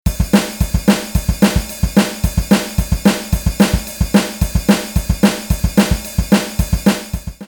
Even though your drums might not be Tama (as the drum sounds above are obtained from a Tama drum kit), please use the same file name to avoid errors when you are using the downloaded Hotstepper file later on.
Comment: This is a fast punk alternative drum pattern.